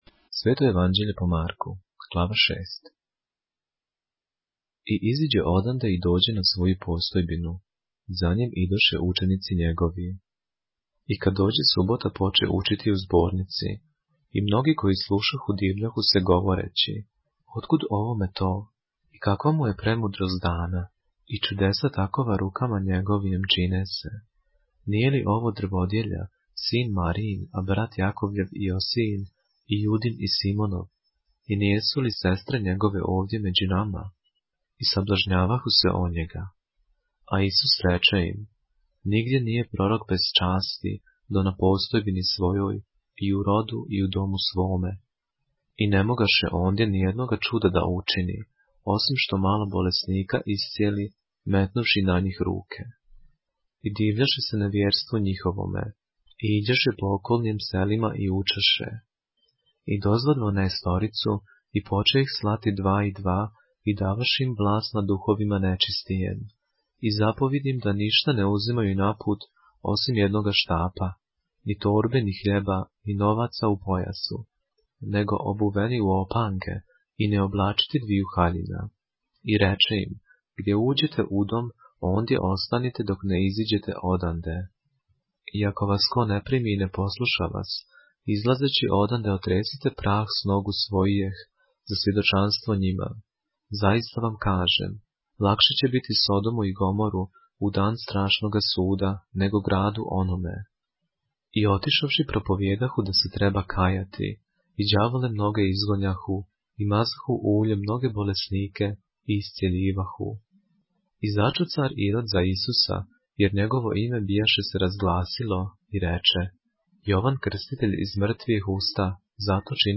поглавље српске Библије - са аудио нарације - Mark, chapter 6 of the Holy Bible in the Serbian language